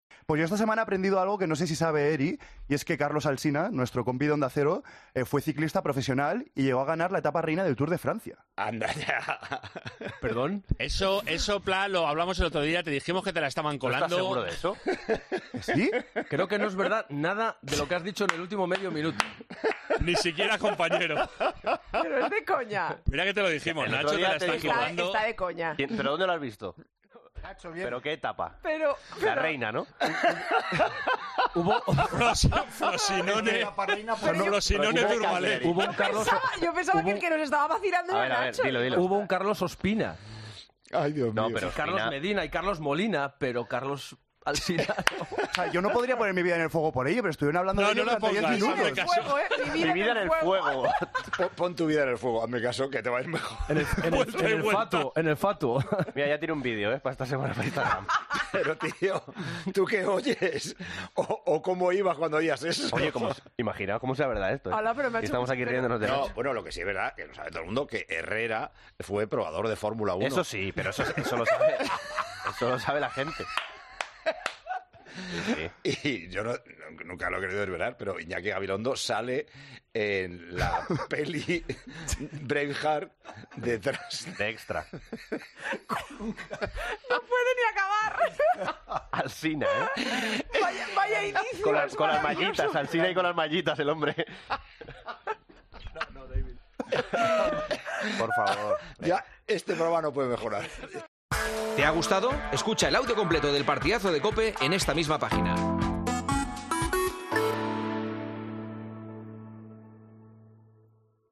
La risa de Paco González al escuchar una historia de un presentador: "Y Carlos Herrera en un F1"
Según lo esbozó en la antena de COPE, Paco González no paró de reírse.